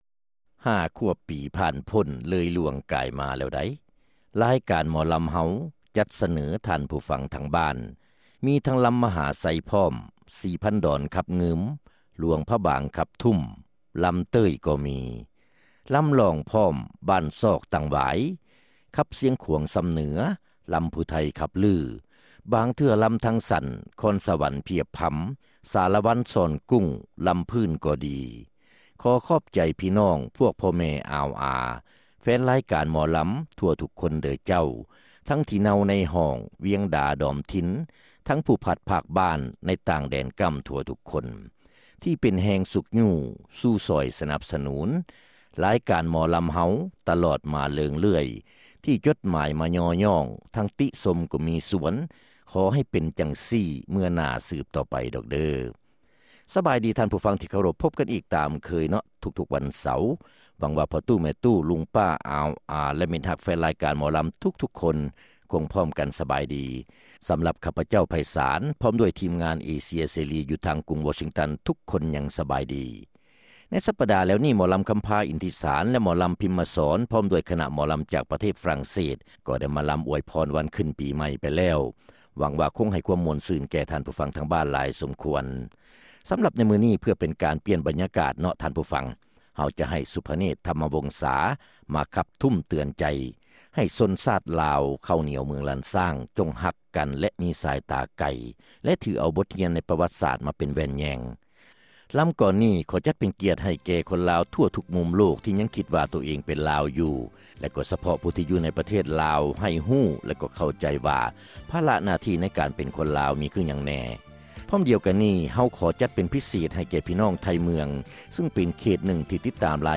ຣາຍການໜໍລຳ ປະຈຳສັປະດາ ວັນທີ 6 ເດືອນ ມົກກະຣາ ປີ 2006